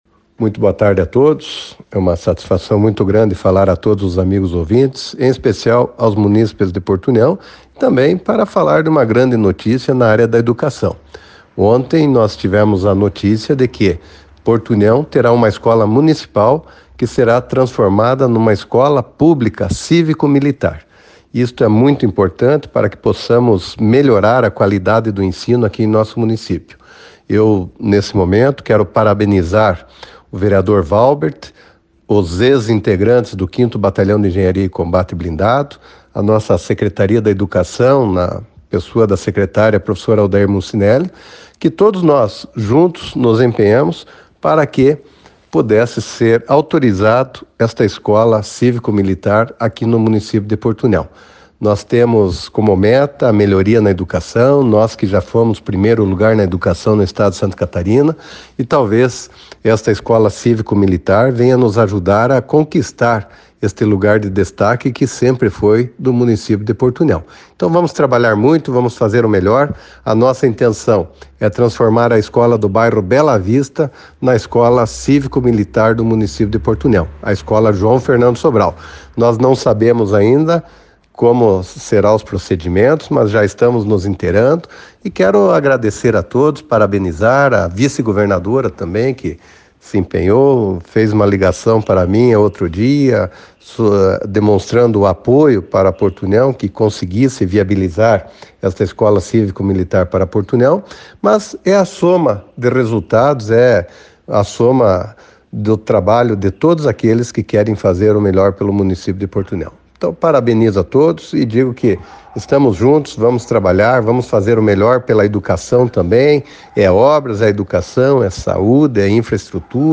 Acompanhe a fala do prefeito Eliseu Mibach abaixo: